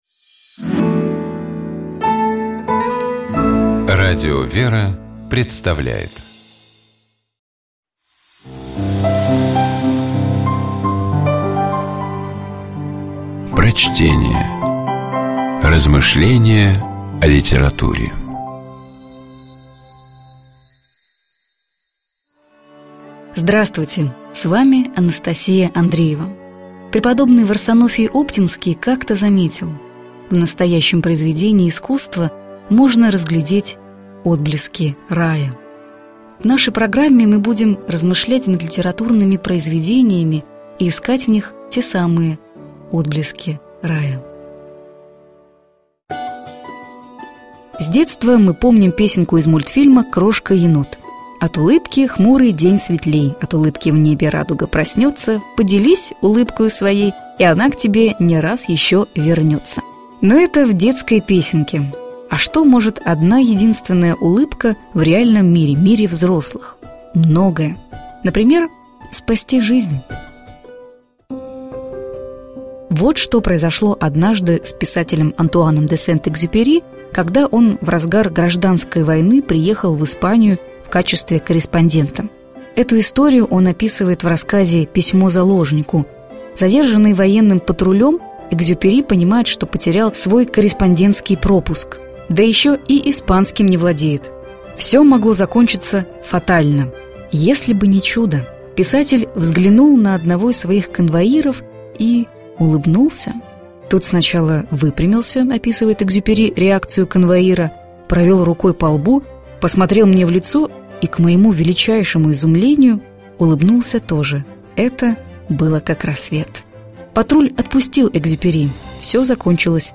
Prochtenie-A_-de-Sent-Jekzjuperi-Planeta-ljudej-Ulybka_-kotoraja-spasla-zhizn.mp3